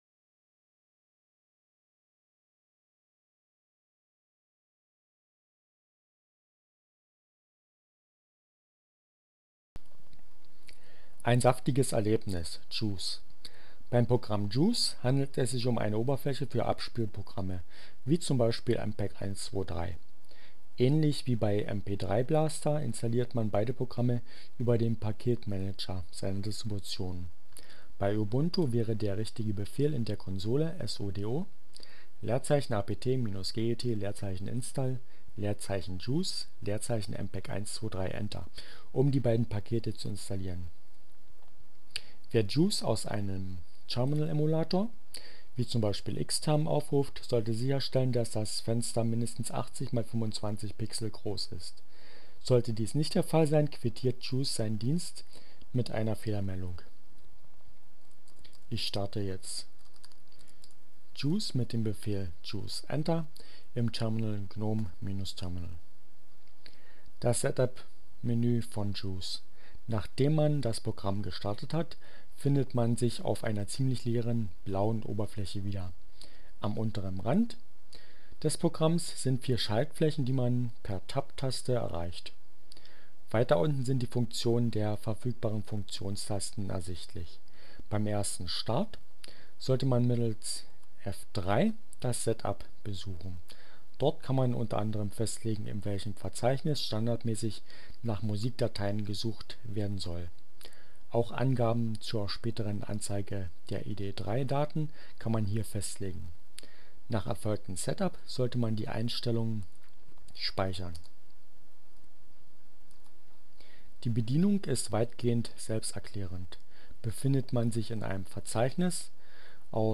Tags: CC by-sa, Gnome, Linux, Neueinsteiger, Ogg Theora, ohne Musik, screencast, ubuntu, juice